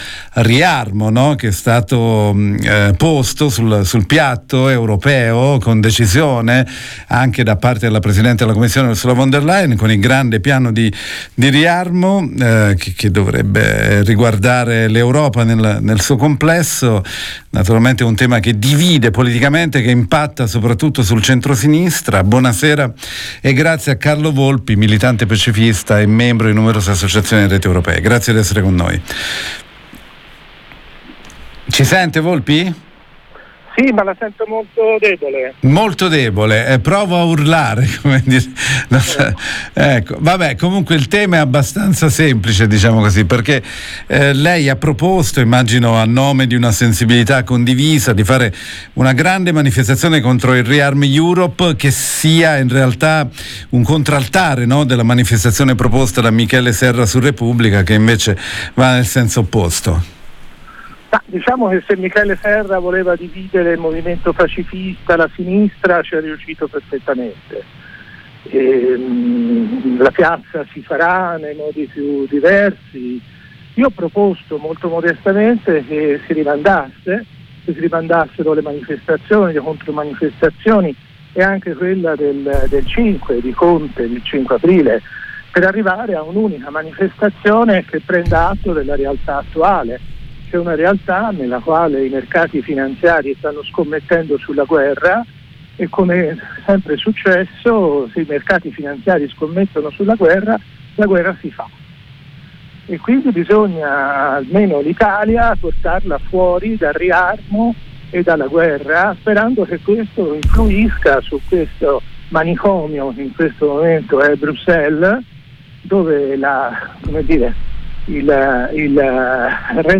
Lo abbiamo intervistato.